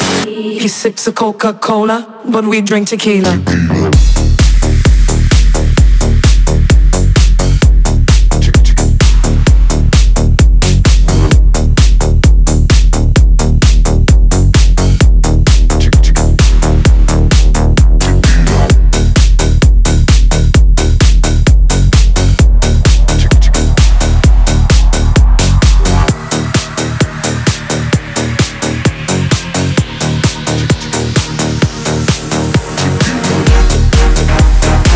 Techno Dance
Жанр: Танцевальные / Техно